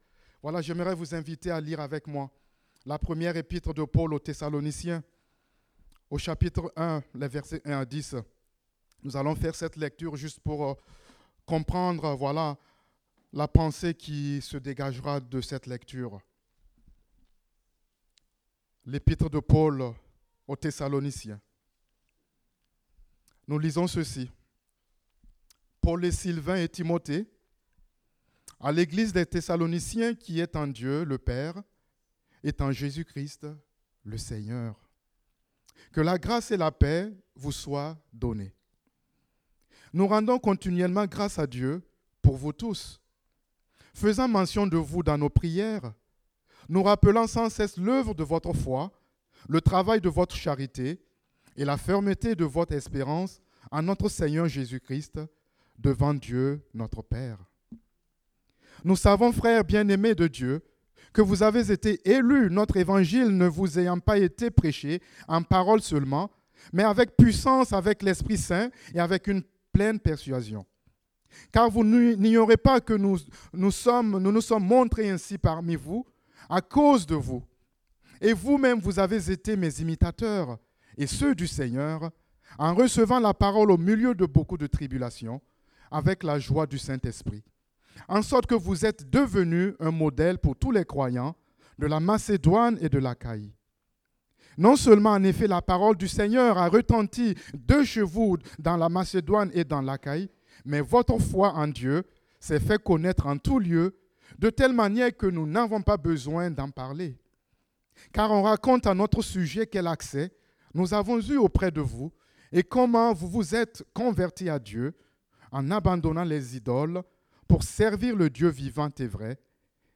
Date : 1 octobre 2017 (Culte Dominical)